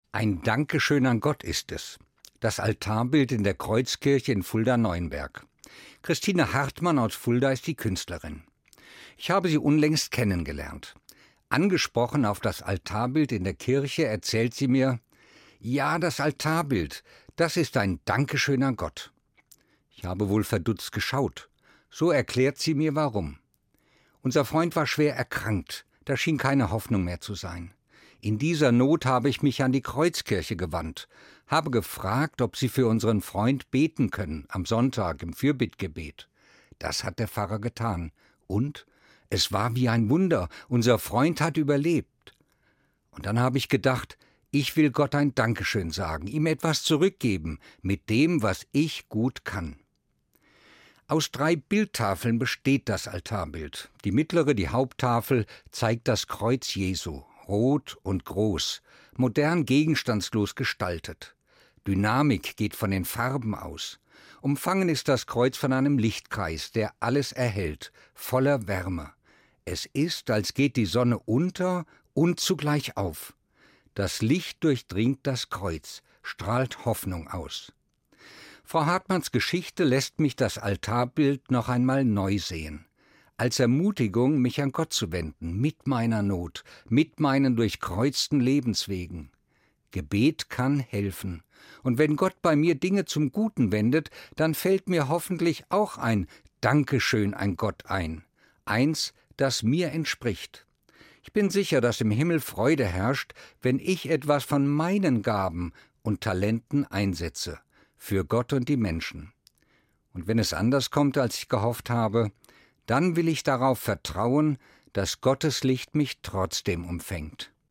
Evangelischer Pfarrer, Fulda